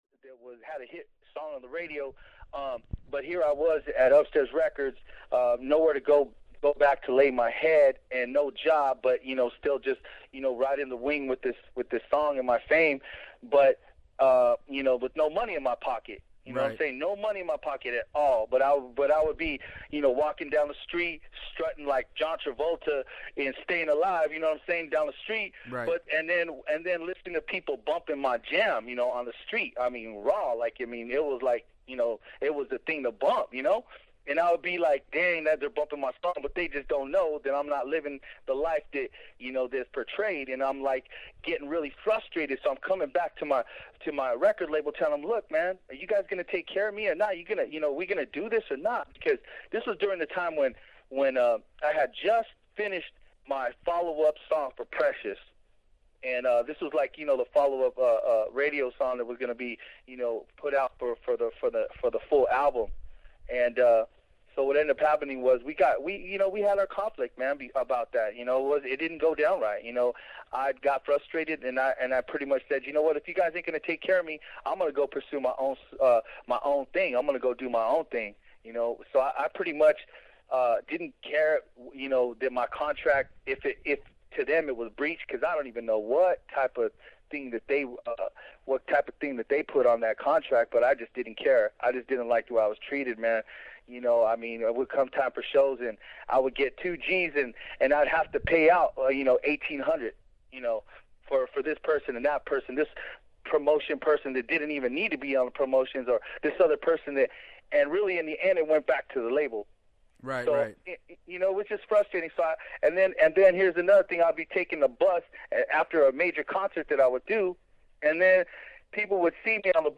Exclusive 2007 Interview